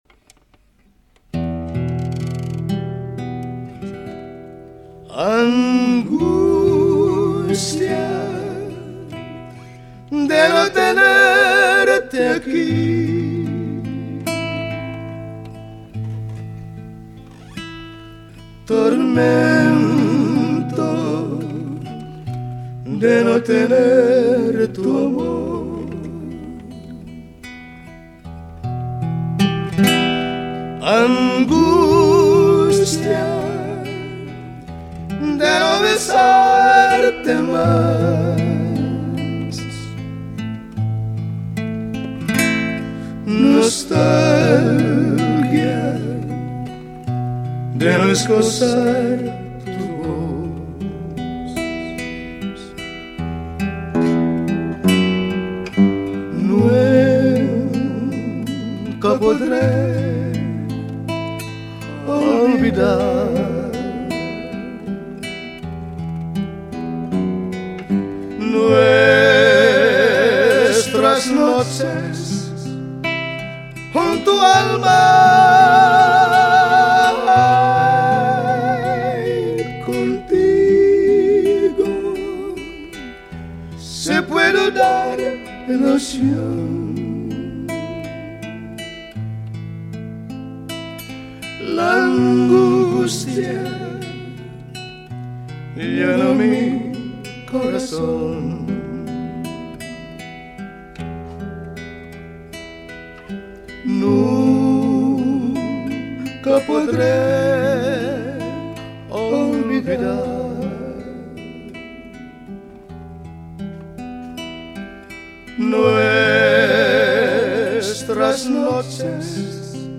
由於該CD中頻段稍嫌硬，在一些搭配失衡的設備上重放時可能太亮。